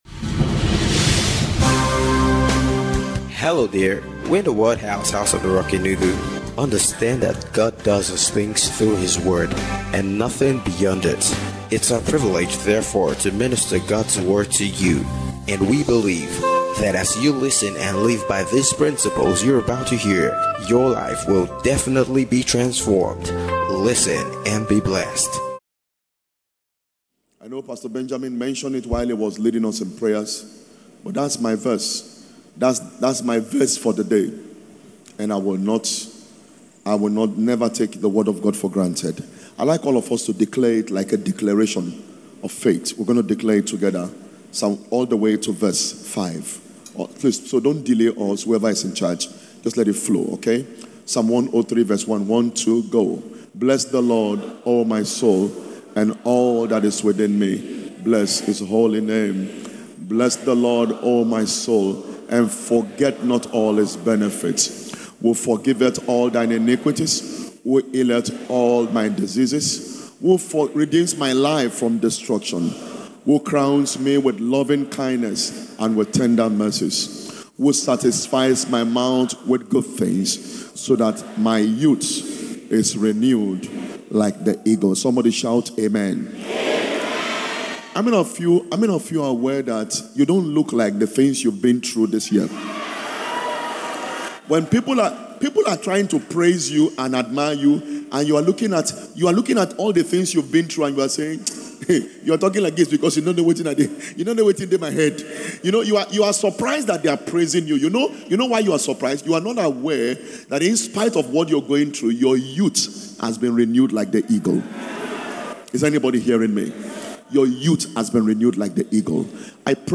Annual Thanksgiving - Fresh Dew Service -